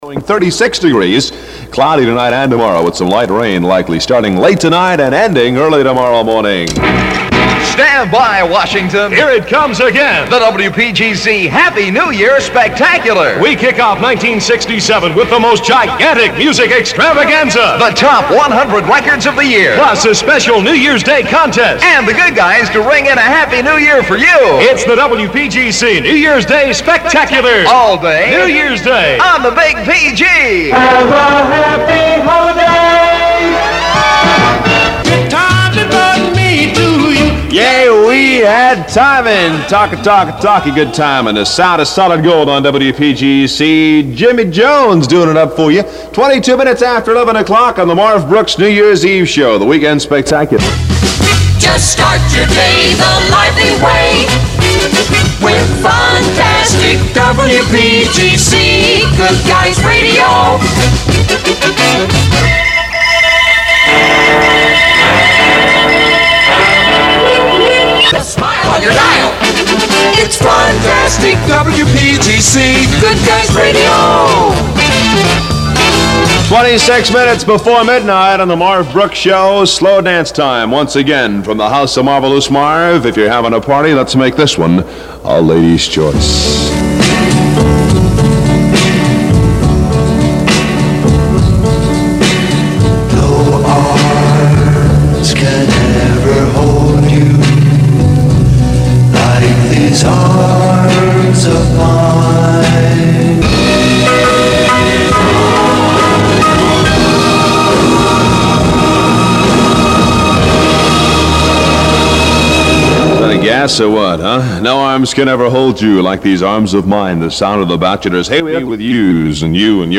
Airchecks
WPGC Play Audio Button 12/3166 New Year's Eve Scoped 8:46